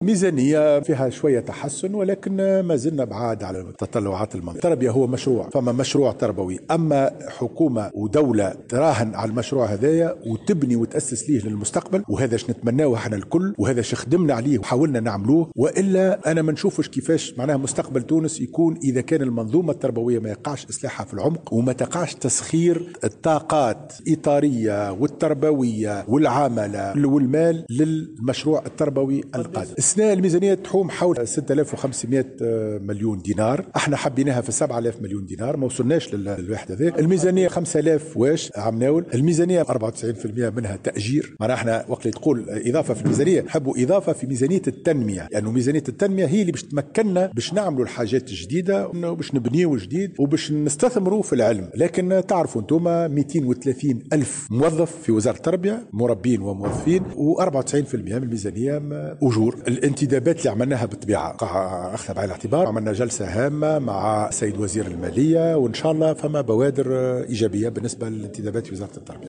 كما لاحظ، بن سالم، على هامش ندوة صحفية عقدت بمركز تكوين المكونين بتونس، اليوم الجمعة، أن المنظومة التربوية لا يمكن لها مجابهة التحدي الديمغرافي الناتج عن زيادة اعداد التلاميذ سنويا في حال عدم اعداد الاستراتيجيات والخطط المناسبة، مؤكدا، أن تقديرات الوزارة تفيد ببلوغ عدد بـ 2 مليون و780 الف تلميذ في أفق العشرية المقبلة وهو ما يتطلب بناء 500 مدرسة جديدة.